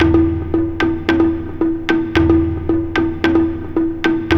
VIET PERC.wav